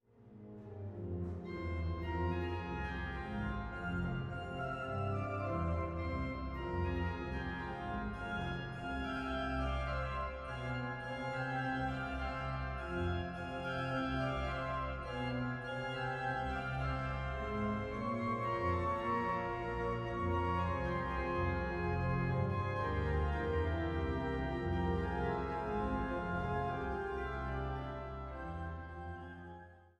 Deshalb wurde diese Werkgruppe für die vorliegende Einspielung an der Hildebrandt-Orgel in Naumburg ausgewählt, da diese eine Manualverteilung auf Haupt- und Oberwerk sowie auf dem Rückpositiv hat.